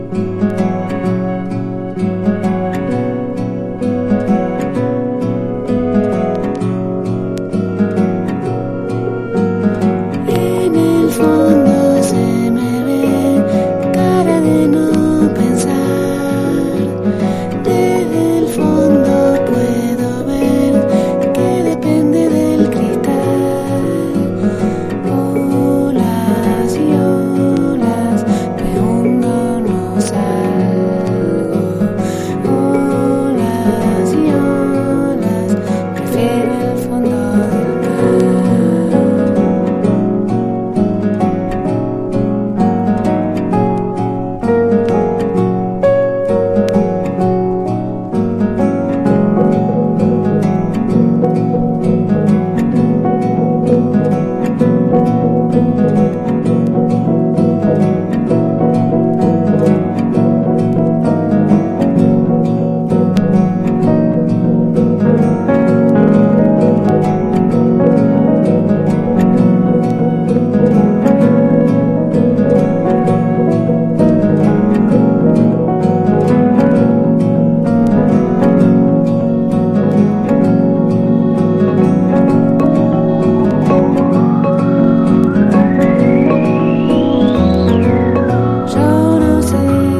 電子音と戯れる幽玄フォークの世界！
暖かな生楽器の響きと電子音の音響的な意匠、聴きなじみのない母国語による唯一無二の音世界。